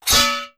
Melee Weapon Attack 6.wav